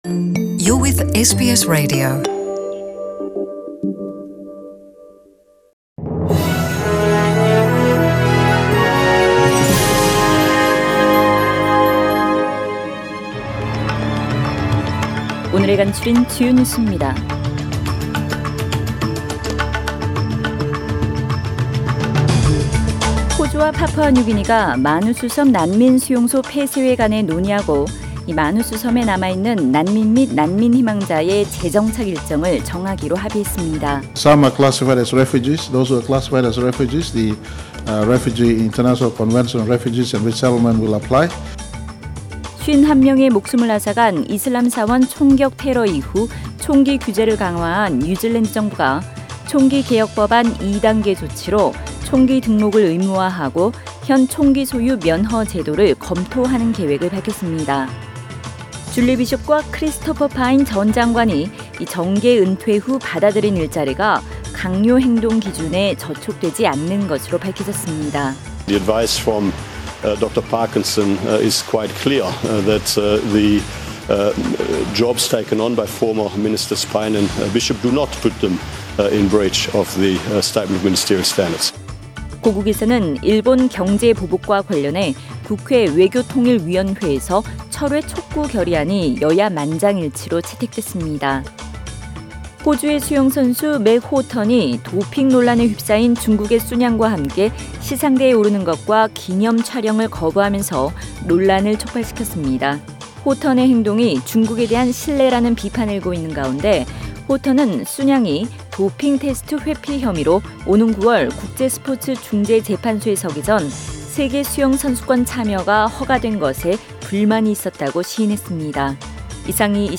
SBS 한국어 뉴스 간추린 주요 소식 – 7월 22일 월요일